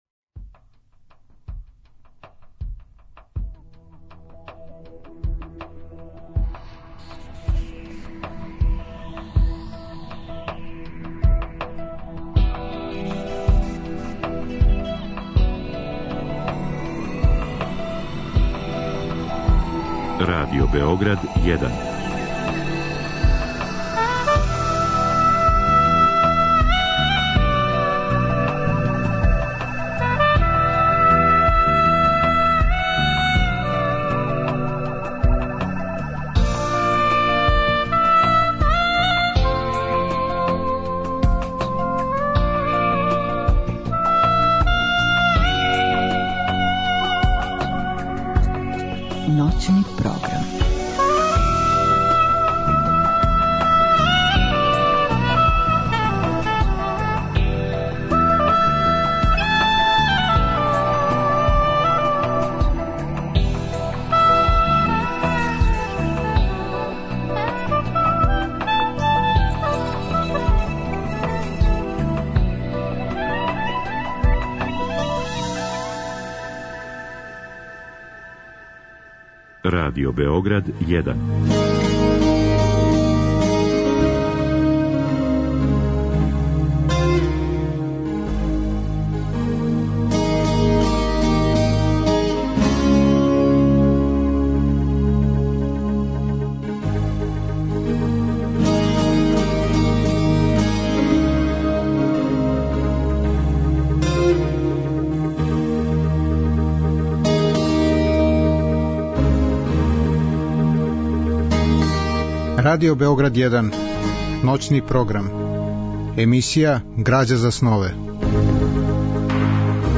Разговор и добра музика требало би да кроз ову емисију и сами постану грађа за снове.
У емисији у ноћи између уторка и среде гост је драмска уметница Рада Ђуричин. Она ће говорити о свом животу, о лепоти глуме и о љубави према књижевности.
У другом делу емисије, од два до четири часa ујутро, слушаћемо делове радио-драме Драгомира Брајковића Опоруке Десанке Максимовић .